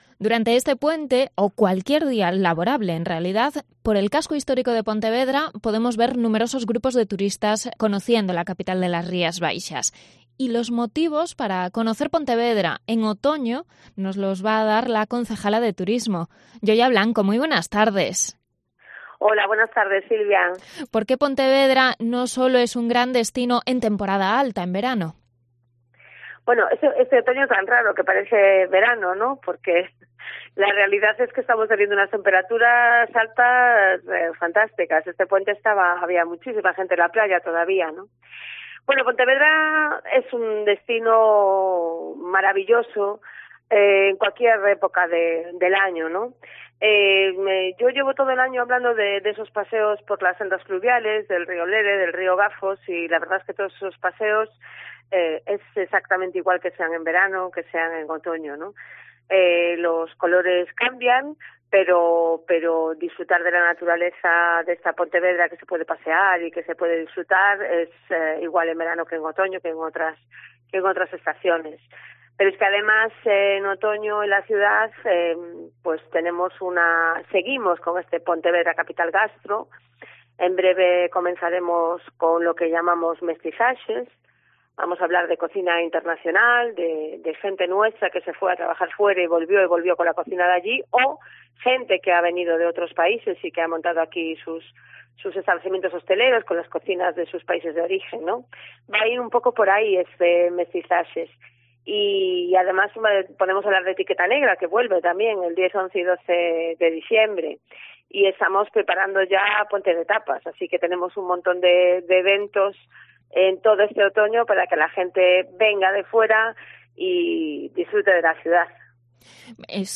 Entrevista a la concejala de Turismo de Pontevedra, Yoya Blanco, sobre la campaña de otoño